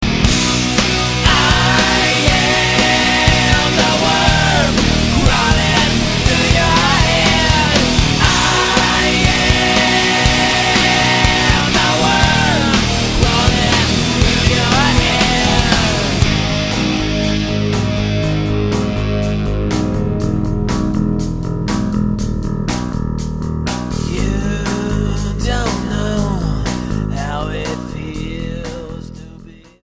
STYLE: Hard Music
hard'n'heavy alternative rock